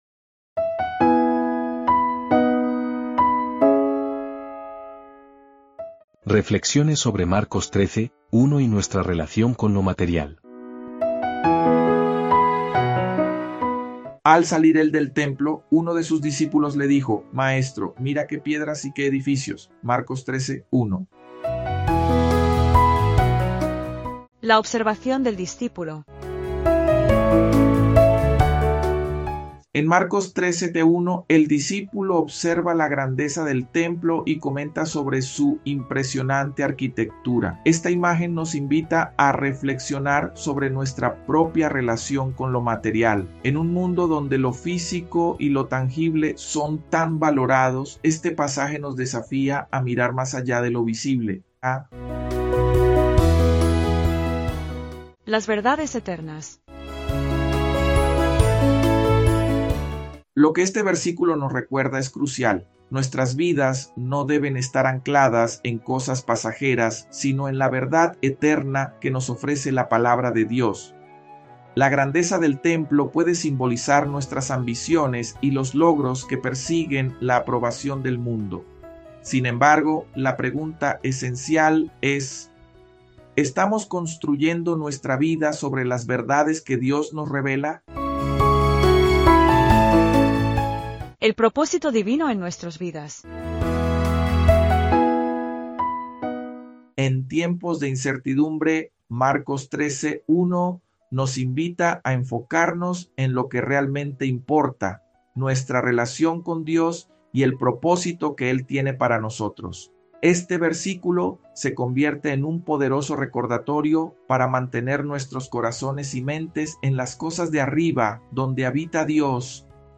Marcos-13.-1-con-musica.mp3